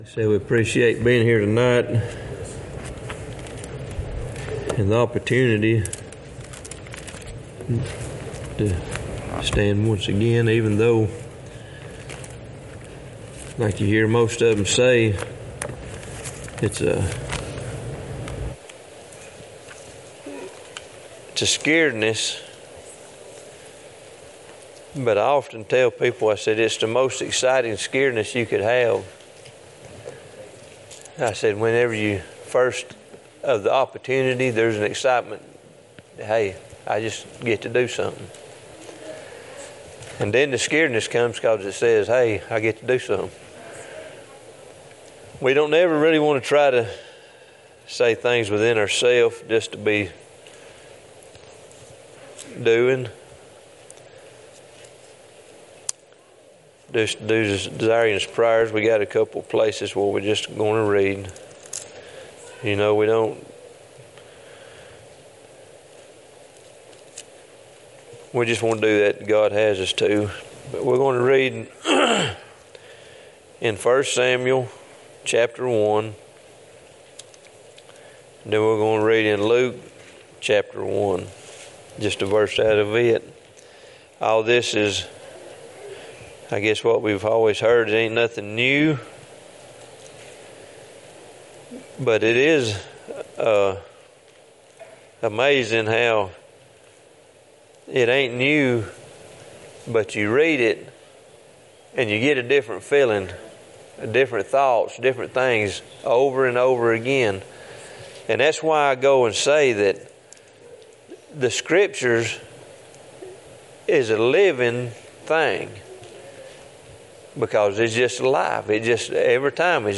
2 Timothy 3:1-17 Service Type: Sunday 2024 Are you fully persuaded?